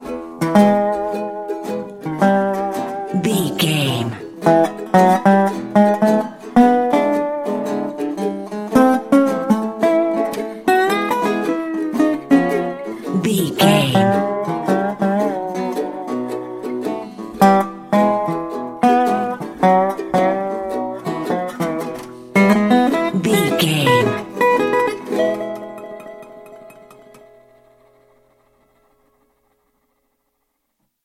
Ionian/Major
acoustic guitar
electric guitar
ukulele
slack key guitar